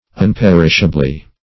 unperishably - definition of unperishably - synonyms, pronunciation, spelling from Free Dictionary Search Result for " unperishably" : The Collaborative International Dictionary of English v.0.48: Unperishably \Un*per"ish*a*bly\, adv.